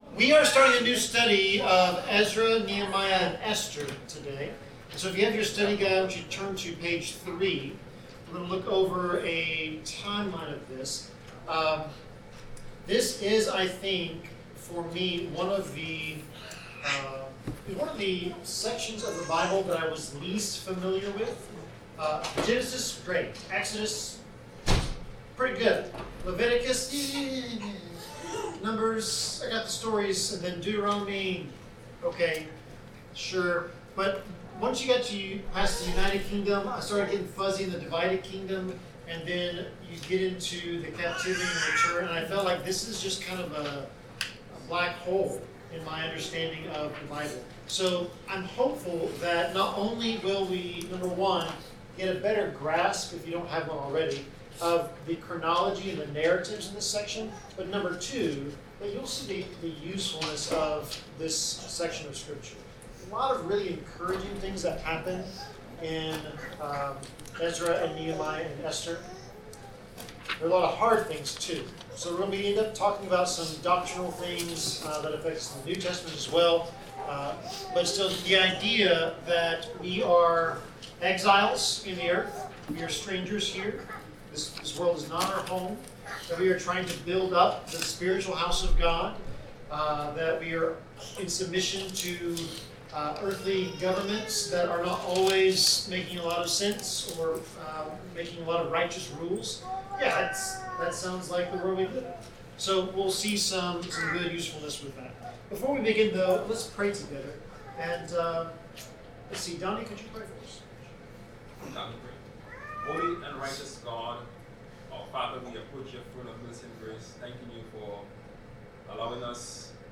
Bible class: Ezra 1-3
Passage: Ezra 1-3 Service Type: Bible Class